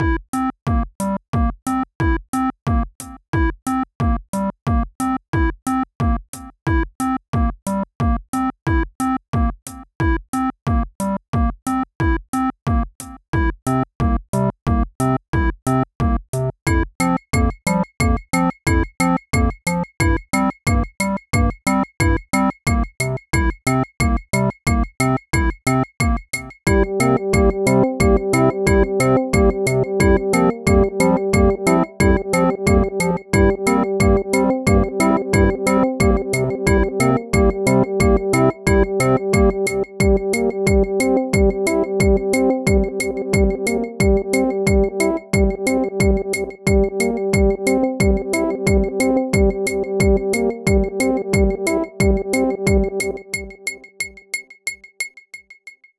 Bucle de Electro-Pop
Música electrónica
melodía
repetitivo
rítmico
sintetizador